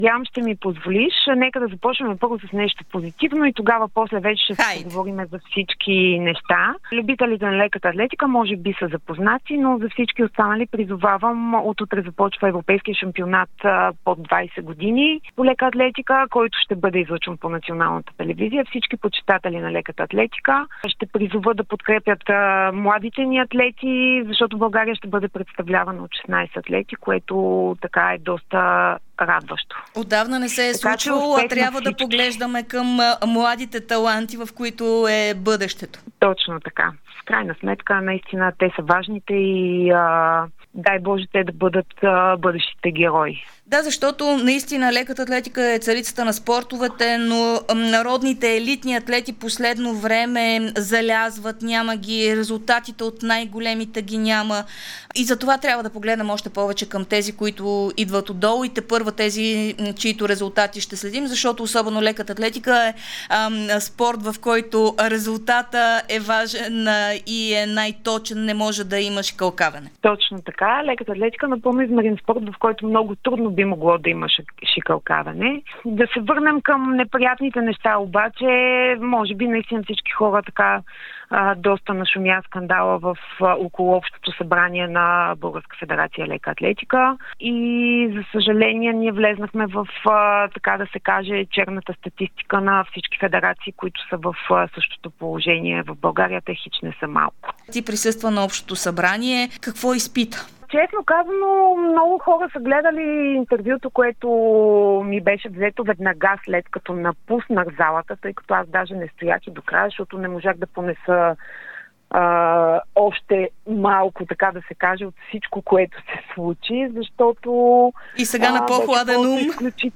Тереза Маринова, олимпийска шампионка в тройния скок от Игрите в Сидни, коментира ексклузивно пред Дарик радио и dsport скандалите около Общото събрание на Българската федерация по лека атлетика, което се проведе на 01.08.2025.